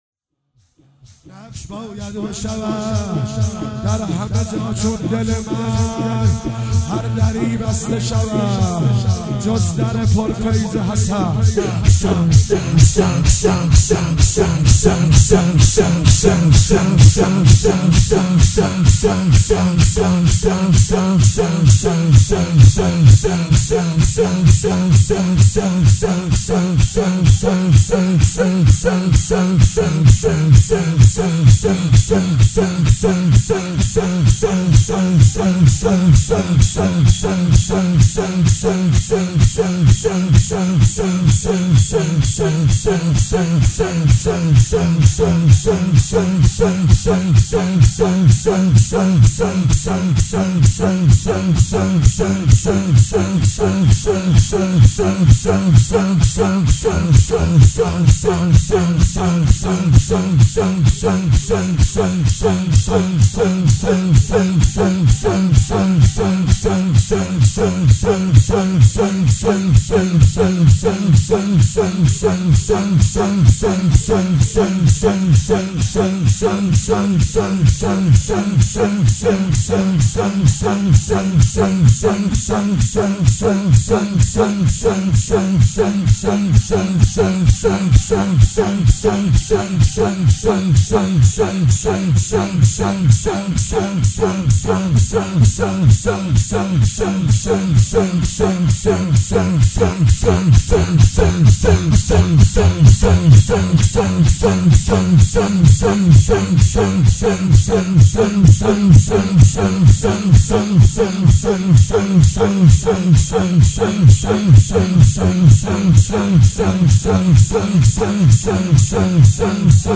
29 صفر 96 - شور - نقش باید بشود چون دل من